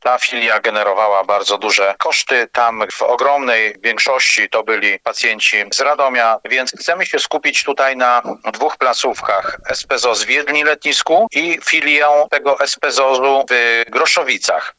Funkcjonowanie oddziału w Radomiu aktualnie jest bezzasadne, mówi burmistrz Jedlni-Letniska Piotr Leśnowolski: